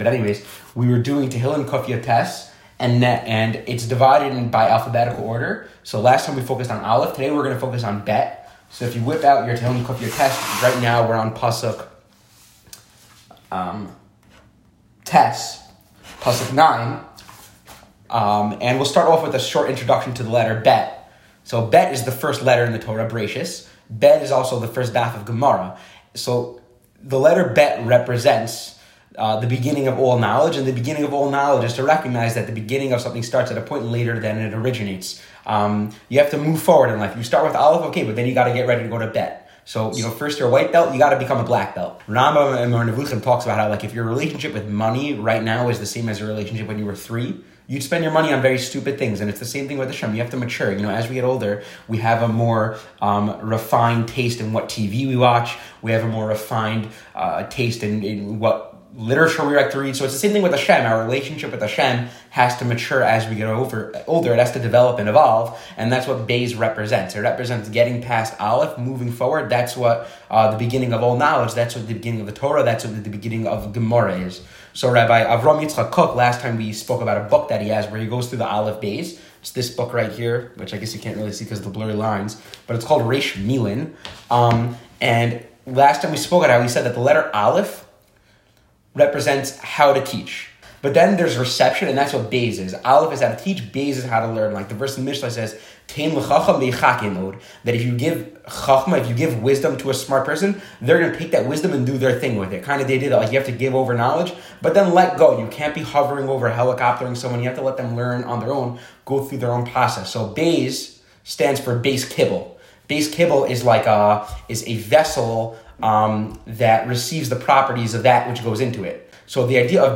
Virtual Tehilim Shiur